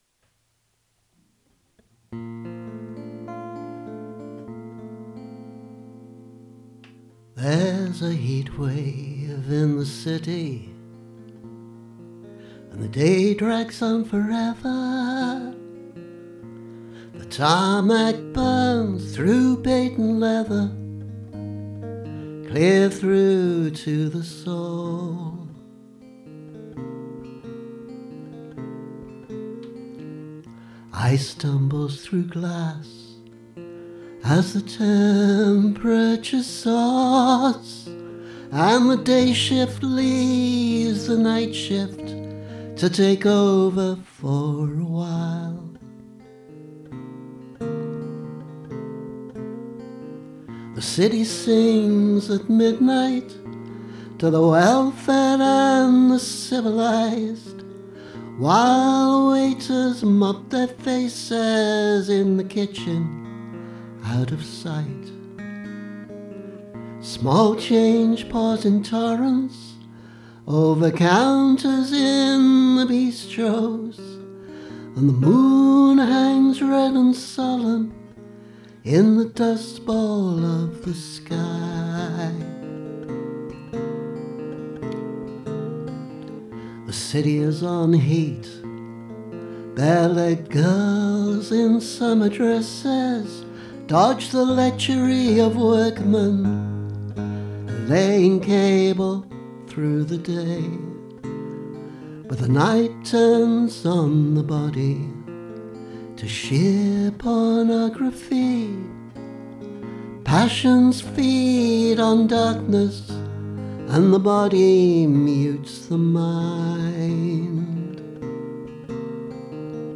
This version is really a demo, as I’m really not happy with the vocal, but it does at least represent the lyric the way I sing it now.